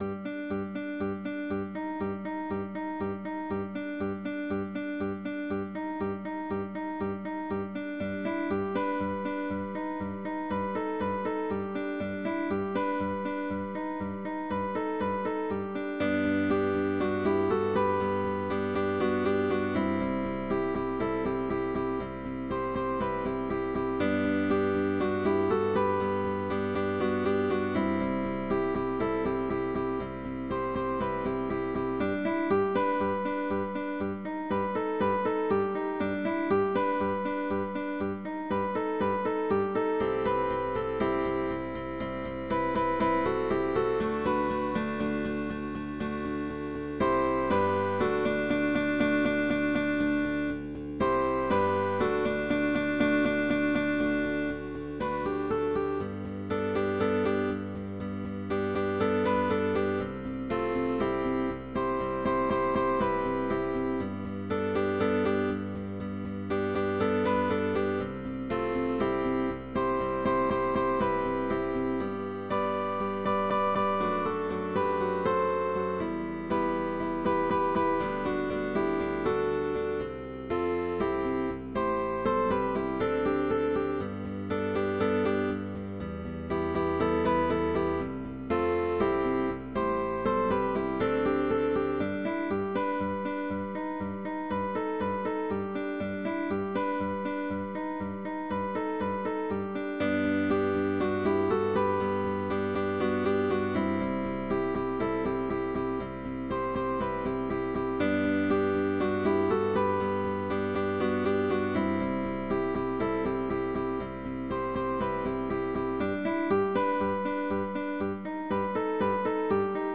CUARTETO de GUITARRAS
Cambios de posición, ligados, arpegios y acordes.